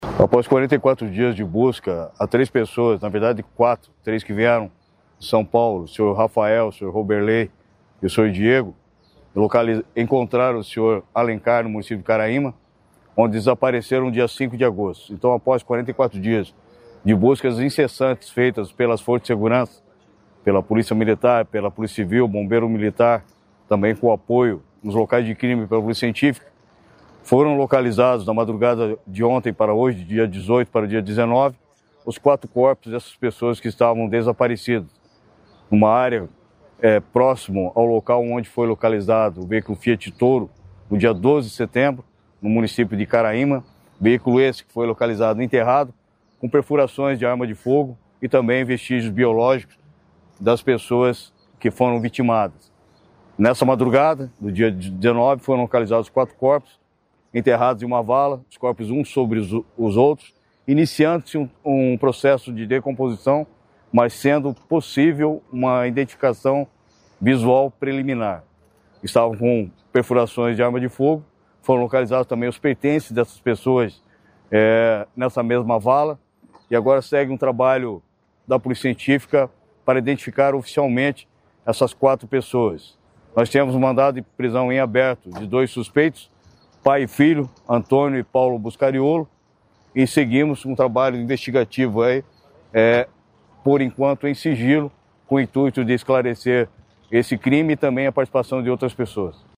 Sonora do secretário Estadual de Segurança Pública, Hudson Teixeira, sobre os quatro corpos encontrados em Icaraíma